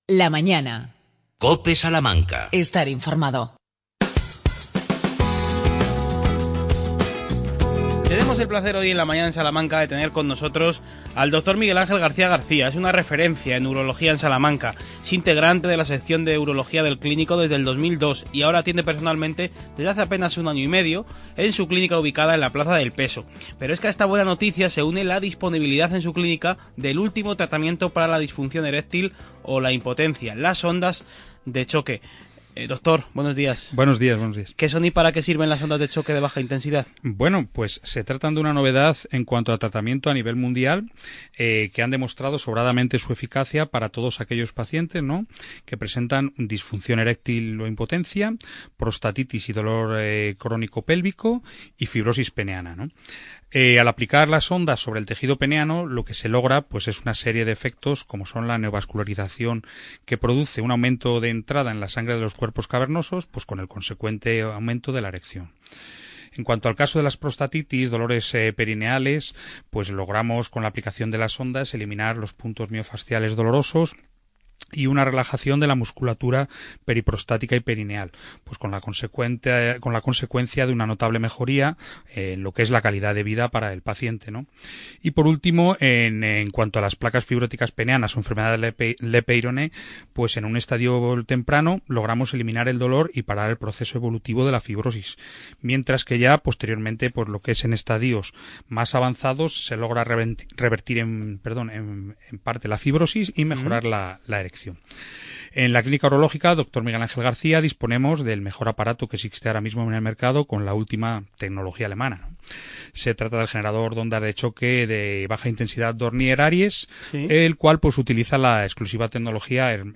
Entrevista en La Cope.